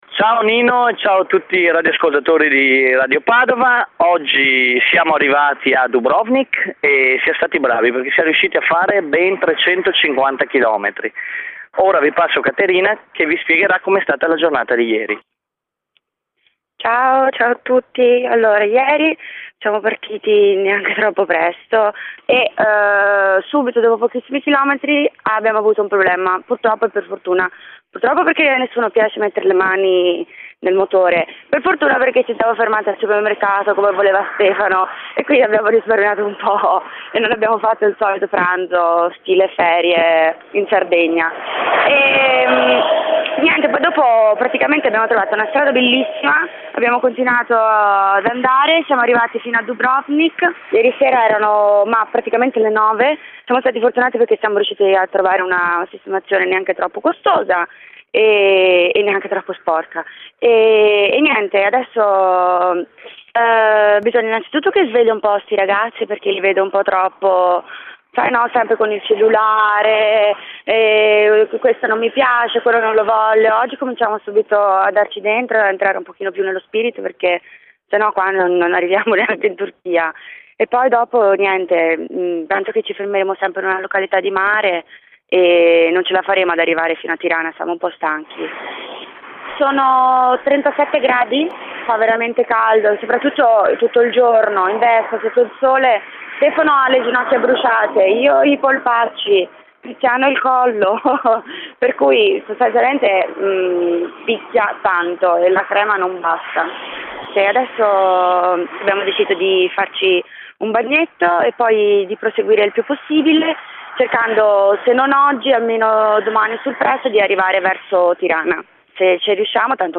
al momento non riesco ad allegare gli MP3 dei collegamenti radiofonici con Radio Padova, ma non disperate... troverò una soluzione...
i potenti mezzi di VOL sono prontamente entrati in soccorso modificando le impostazioni del server ed è ora possibile allegare file MP3 anche di un certo peso! Ecco quindi i file degli interventi successivi:
Buon ascolto dalla viva voce dei protagonisti!